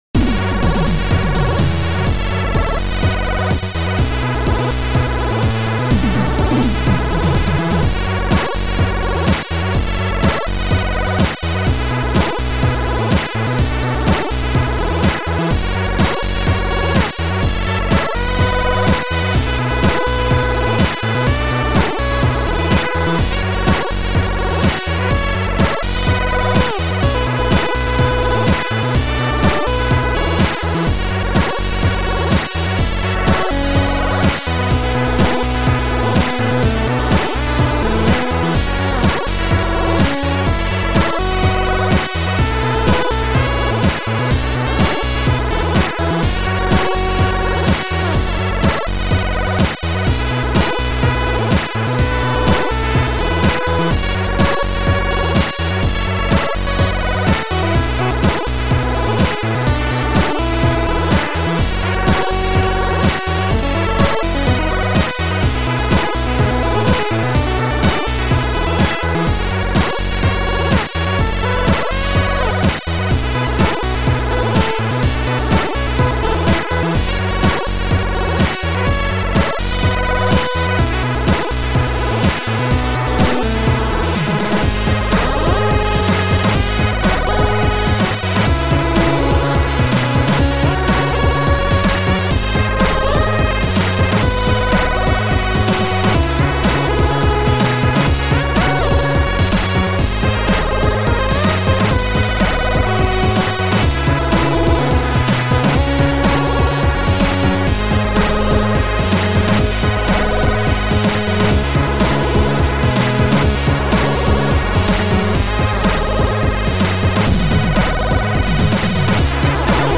Noisetracker/Protracker
Chip Music Pack